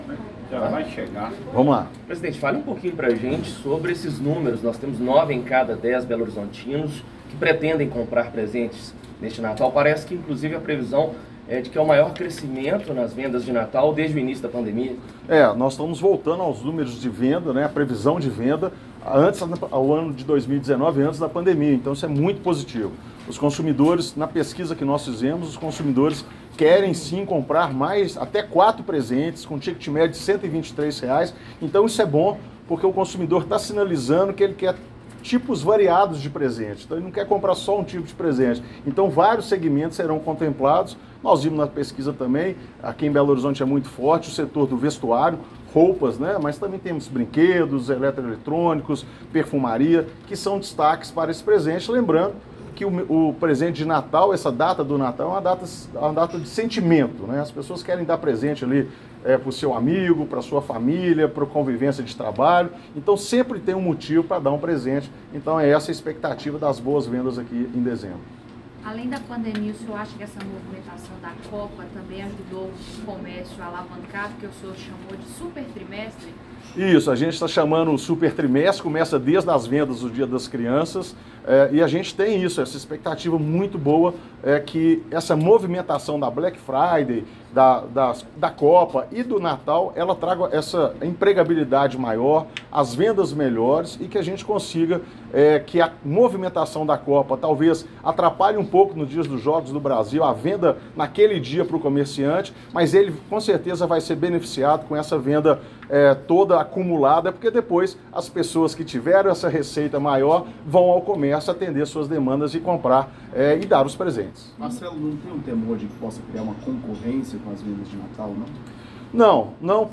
Coletiva-de-Imprensa-Economia
Coletiva-de-Imprensa-Economia.mp3